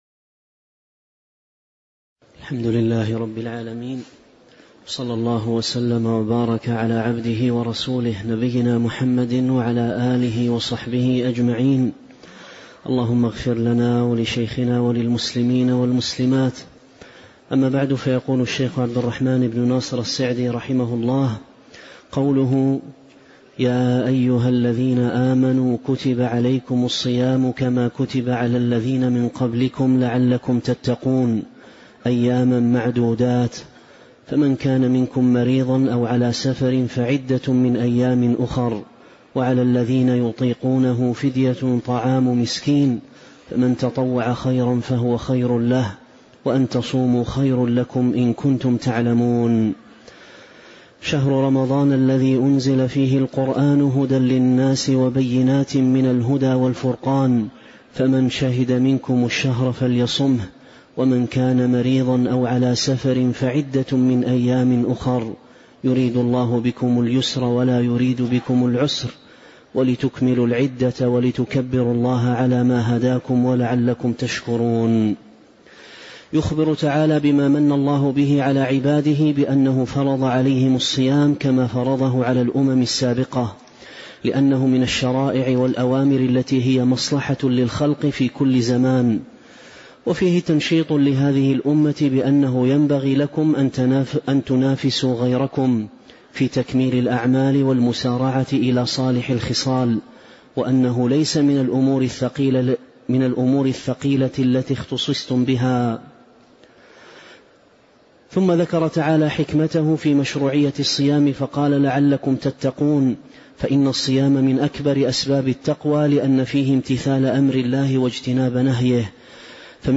تاريخ النشر ١٤ رجب ١٤٤٦ هـ المكان: المسجد النبوي الشيخ: فضيلة الشيخ عبد الرزاق بن عبد المحسن البدر فضيلة الشيخ عبد الرزاق بن عبد المحسن البدر تفسير سورة البقرة من آية 183 (070)